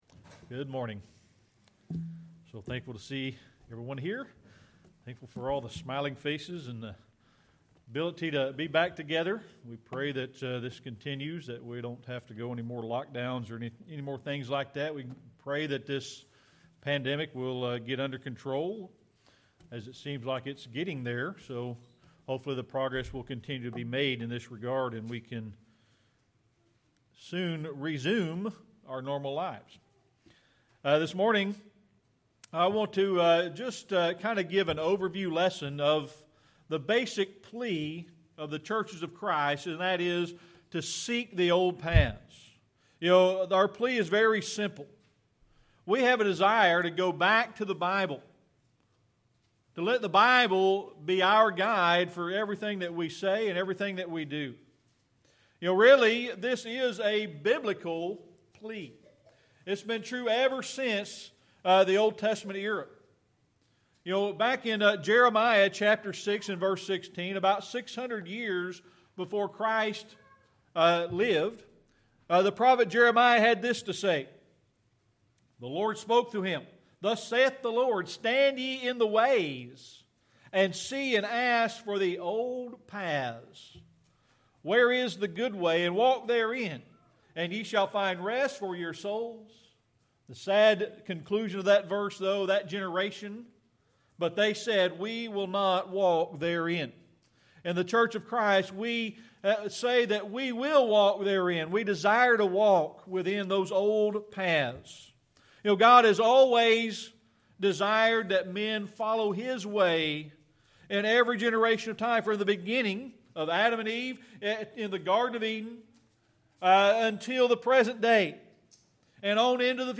Jermiah 6:16 Service Type: Sunday Morning Worship The basic plea of the churches of Christ